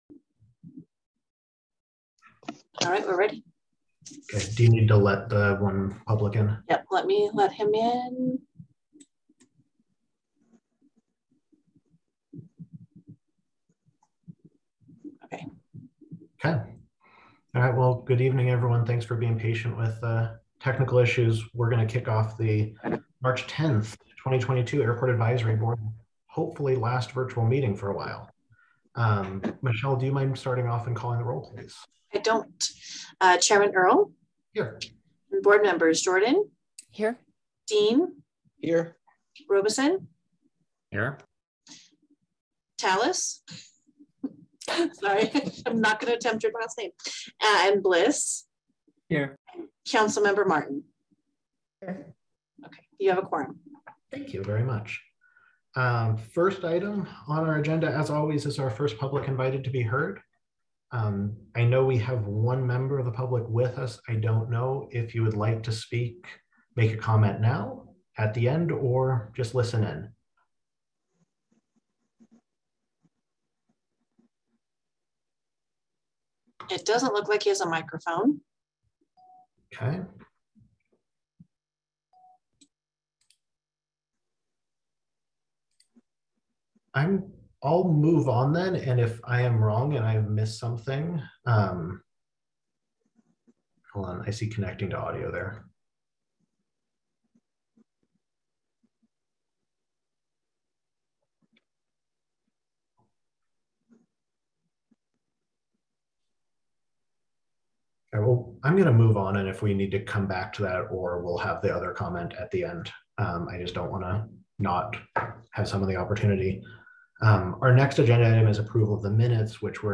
The Airport Advisory Board Meeting recorded on March 10, 2022